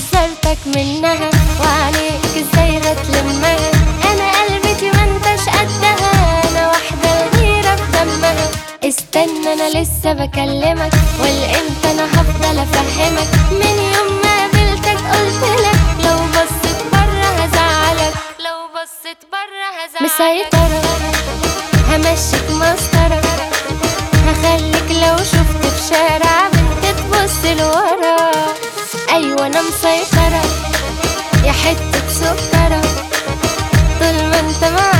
Жанр: Поп
# Arabic Pop